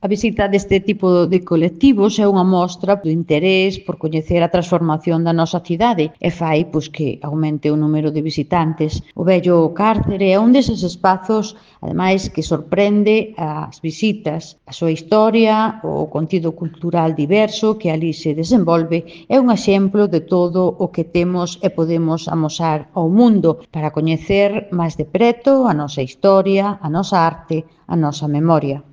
Audio A concelleira de Cultura, Turismo e Promoción da Lingua, Maite Ferreiro, sobre o colectivo de xubilados da CIG | Descargar mp3